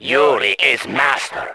A Simple edit of the initiate audio from YR to have the higher-pitched voice that played along with their voice during that scene in the YR Intro.